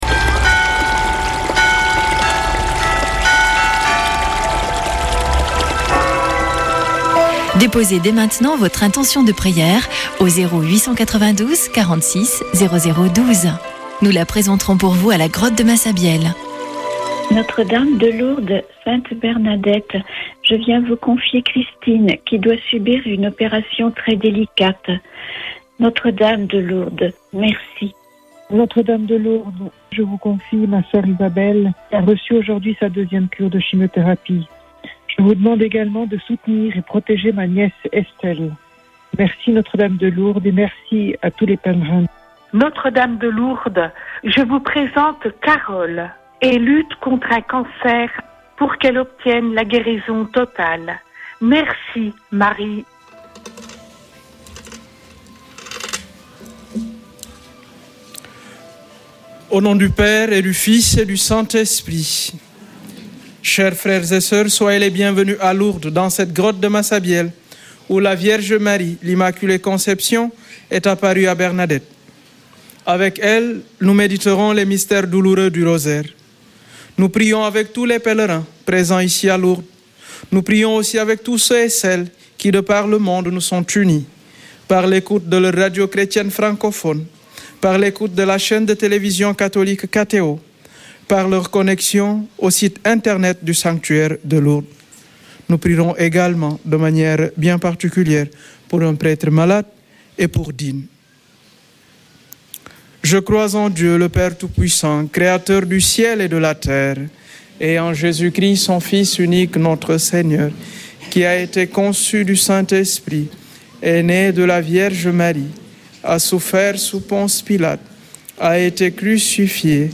Chapelet de Lourdes du 27 mars